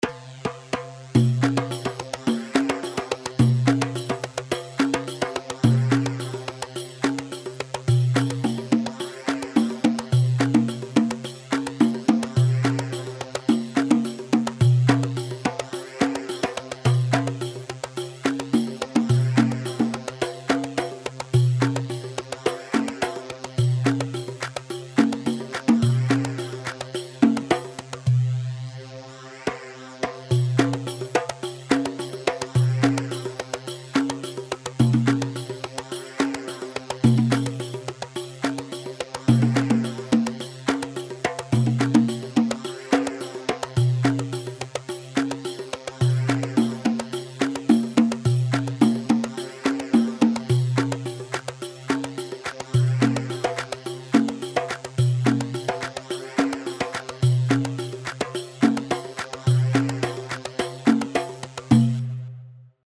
Yemeni
Ghaya 4/4 214 غية